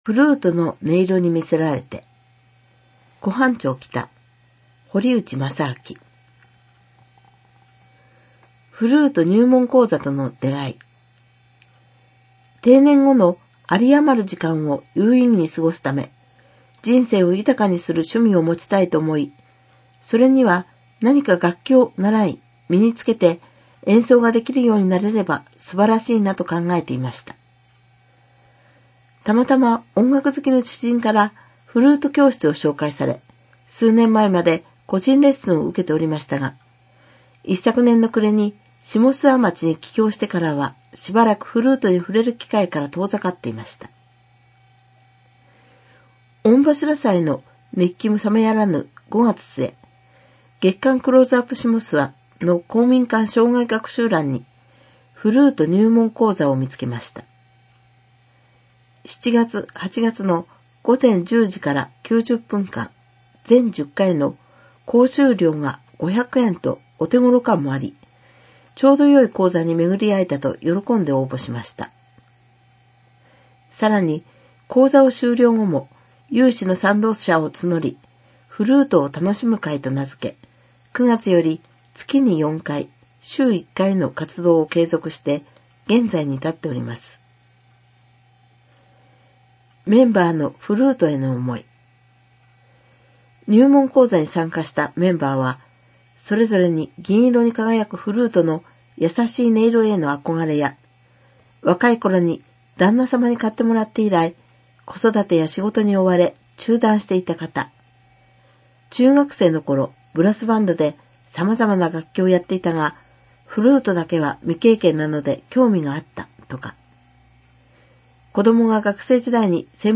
（音読版）クローズアップしもすわ 2017年6月号 表紙～内容紹介 [ mp3 type：1035KB ] （音読版）クローズアップしもすわ 2017年6月号 記事１ [ mp3 type：11MB ] （音読版）クローズアップしもすわ 2017年6月号 記事２ [ mp3 type：12MB ] （音読版）クローズアップしもすわ 2017年6月号 記事３ [ mp3 type：8MB ]